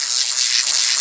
lightningloop.wav